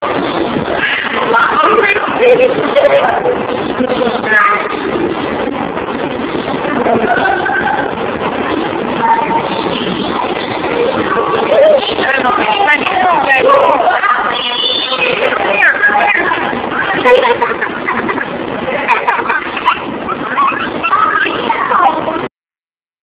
These are a series of short clips from a VHS video shot at the Erie Zoo, Erie Pennsylvania in the late 1980's The videos are in real video format.
Prarie Dog